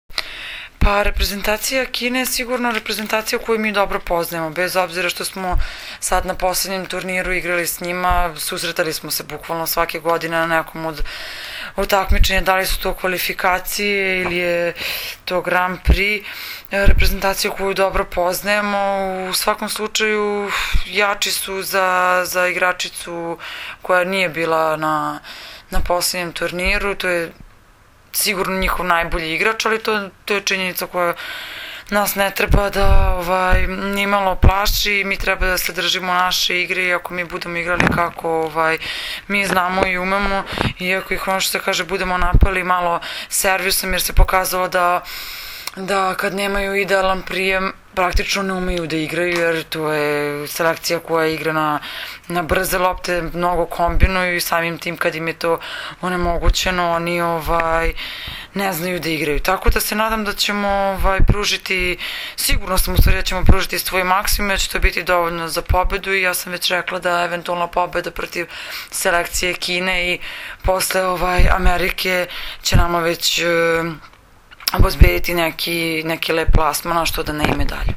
IZJAVA MAJE OGNJENOVIĆ 1